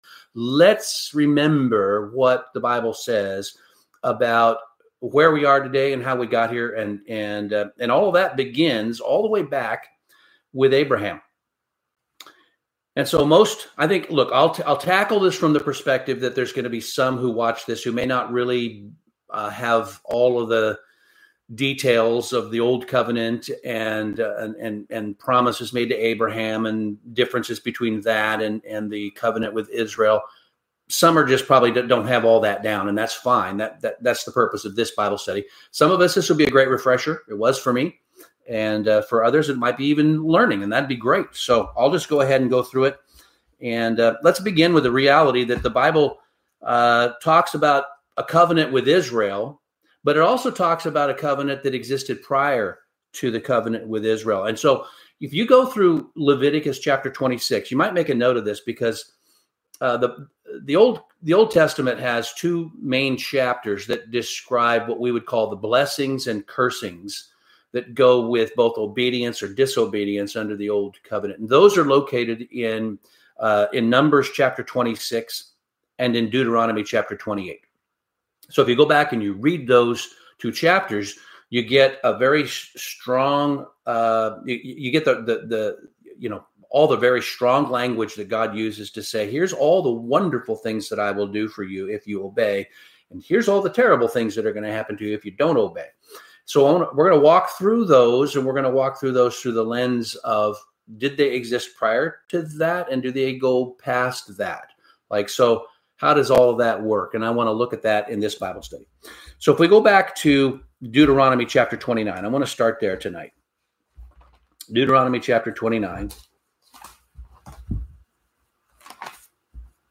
Bible Study - Abraham's Birthright Promise